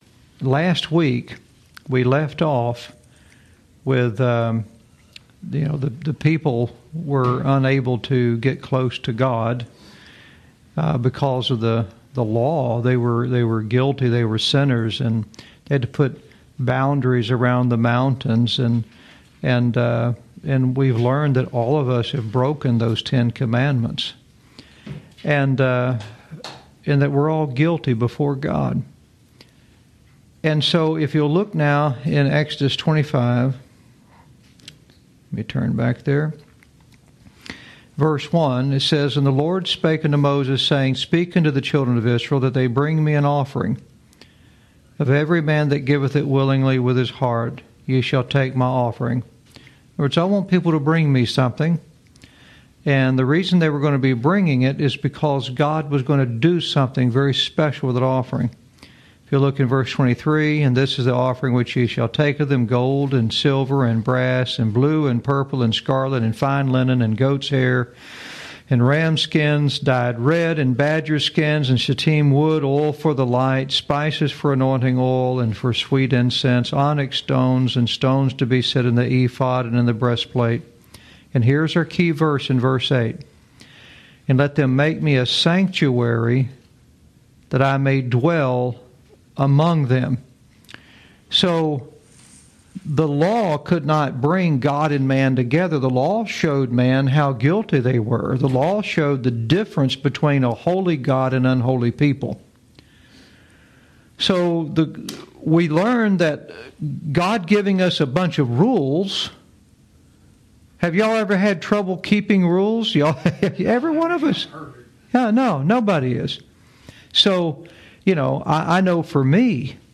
Lesson 24